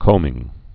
(kōmĭng)